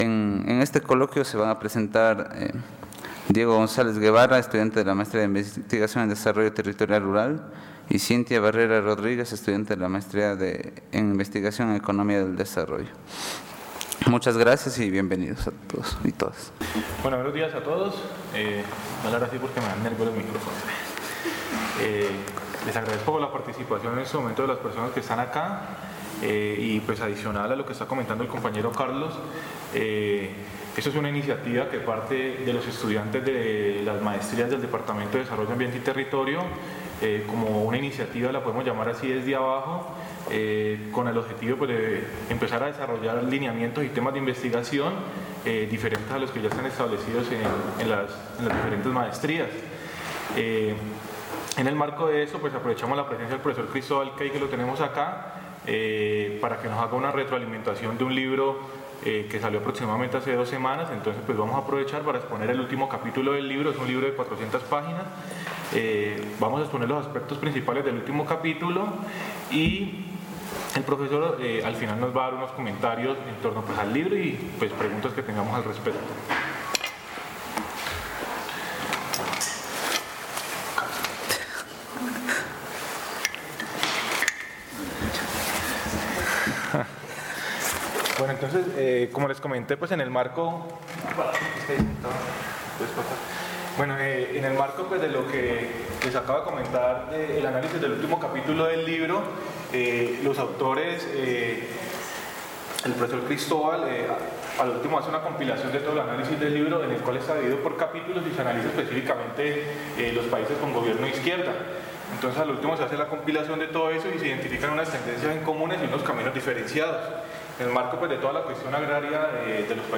Coloquio de investigación